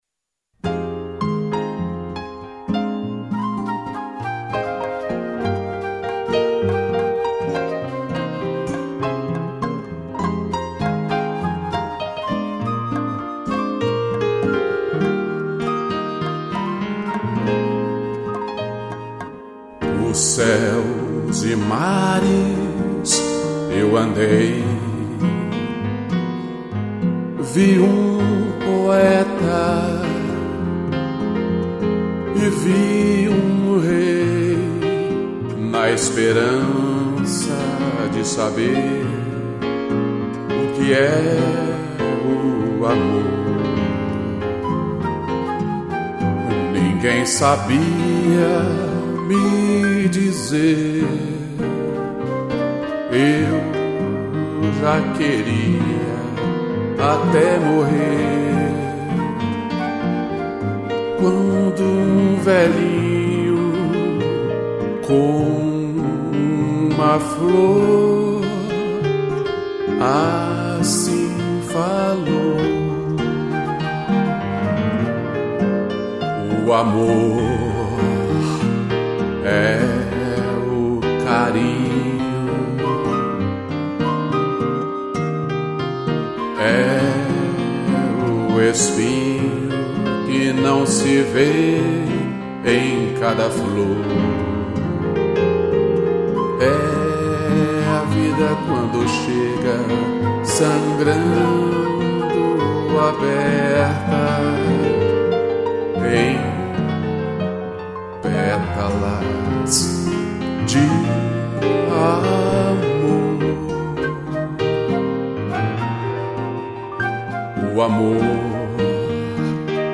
voz e violão
piano e flauta